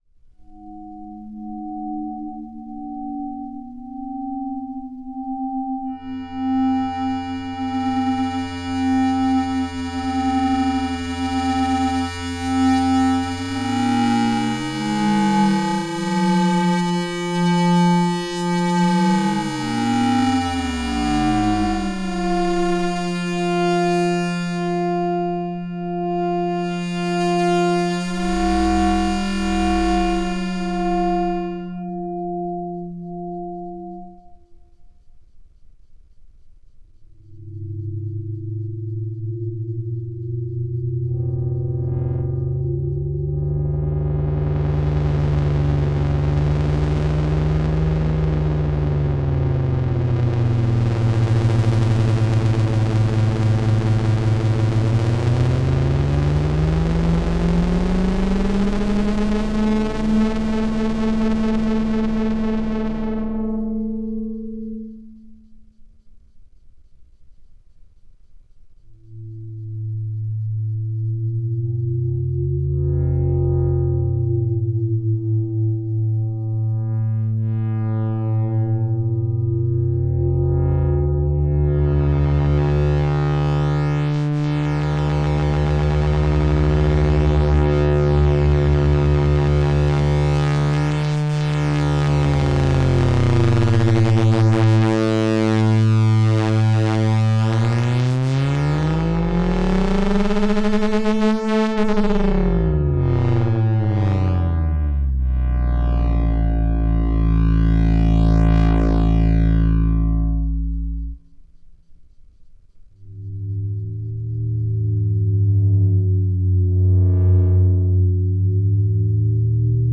So the current approach is to use the above formula, but make osc_1 and osc_2 a fixed pitch of A440, which of course can already be offset from osc_0 and each other in a variety of ways, have FM applied, etc.  It's the A440 thing that feels like a bit of a kludge, but it does work, and I can't come up with an alternative.
[EDIT2] A demo of those presets: